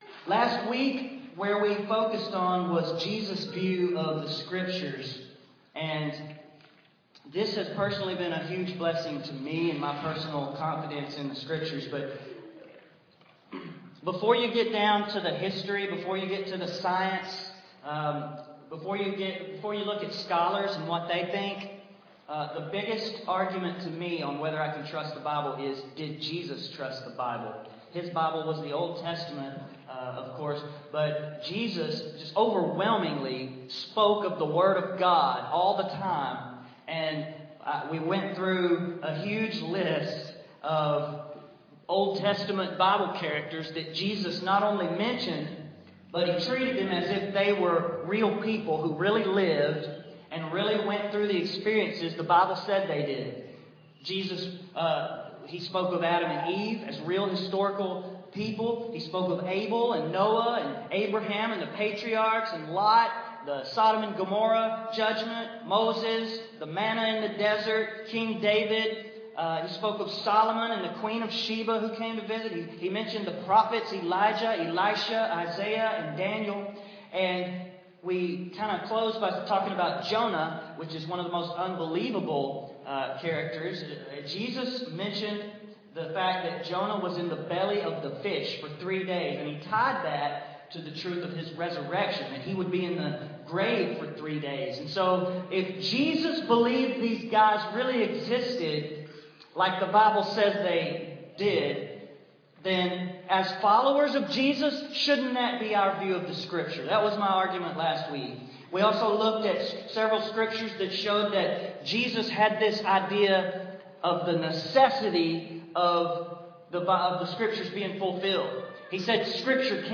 The second message in our Can We Trust the Bible? series. What did Jesus' Apostles believe and teach us about the Scriptures?